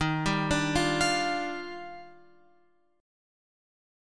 D# Major 9th